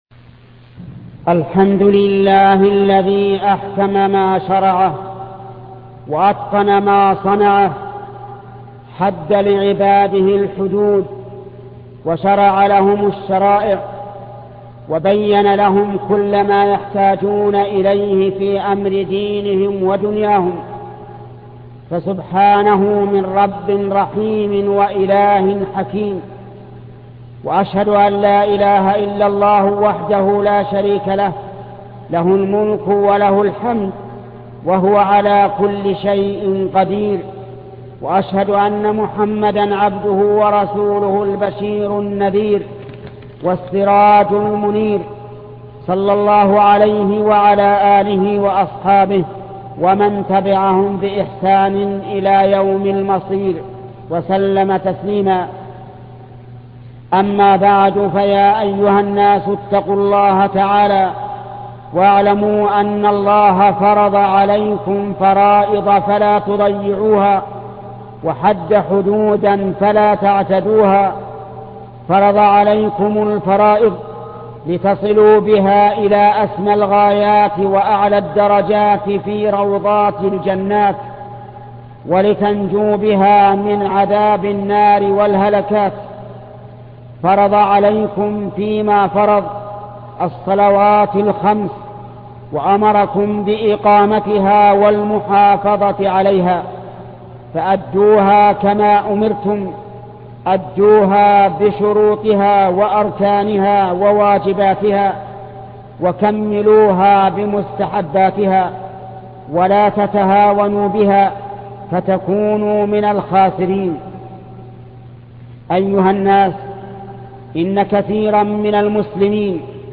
خطبة إتقان العبادة الشيخ محمد بن صالح العثيمين